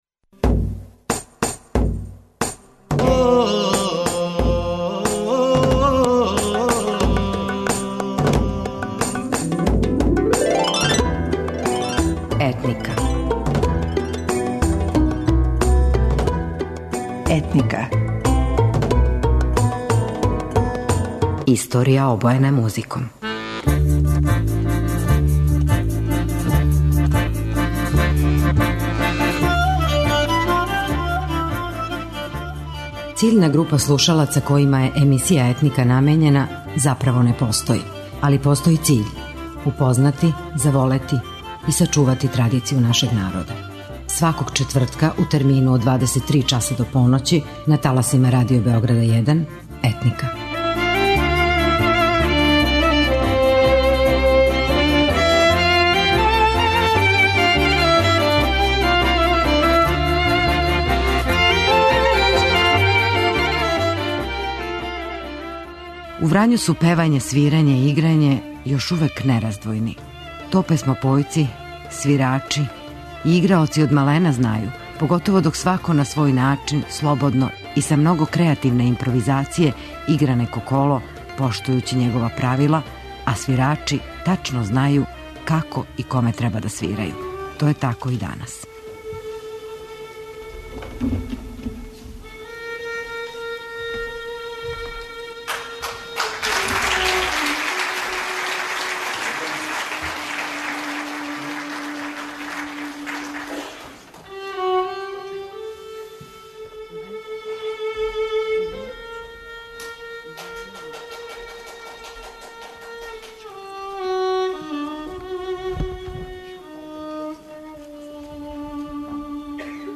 Врањска народна песма уписана је у регистар националног културног наслеђа. Тиха, носталгична, натопљена жалом за младошћу, дертом... осећајна, пуна севдаха и мерака.
Пратиле су је виолине, кларинет и трубе.